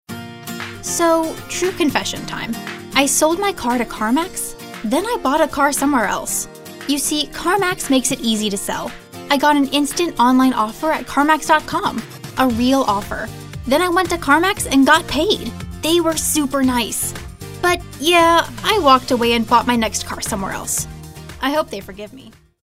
anti-announcer, conversational, cool, friendly, genuine, girl-next-door, real, sincere, storyteller, sweet, upbeat, young, young adult